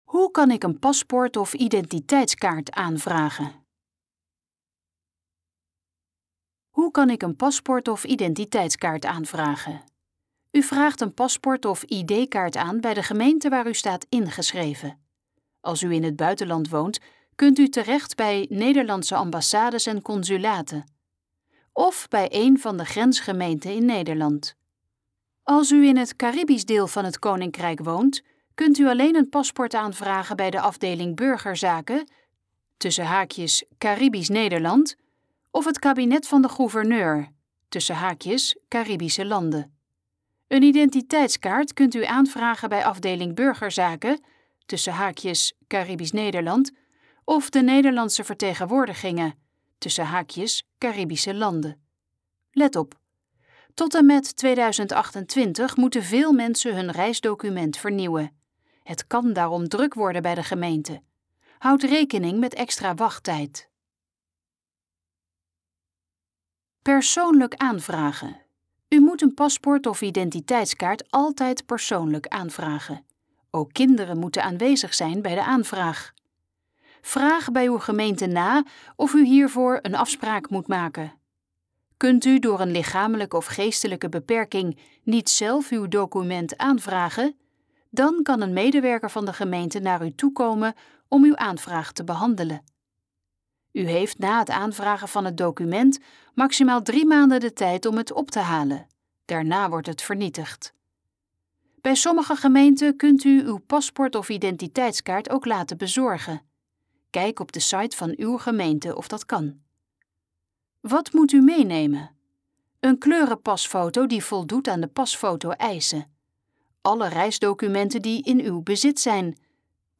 Dit geluidsfragment is de gesproken versie van de pagina Hoe kan ik een paspoort of identiteitskaart aanvragen?